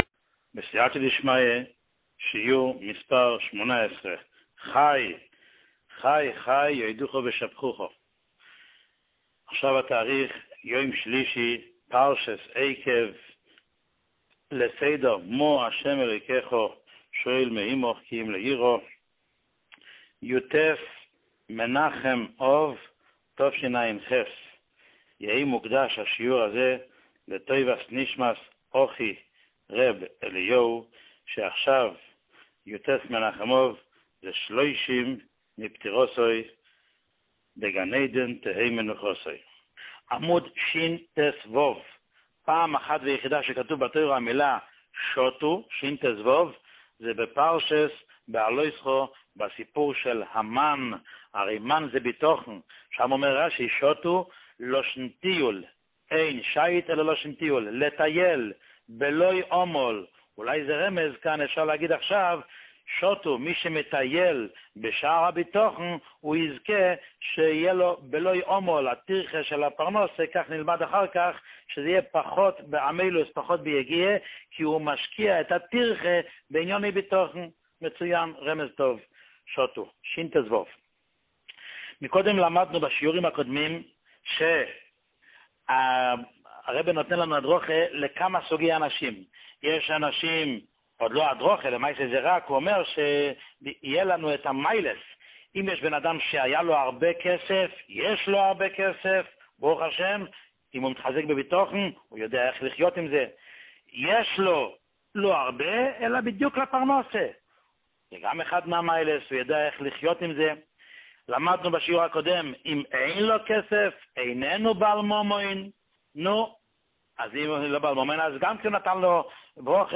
שיעור 18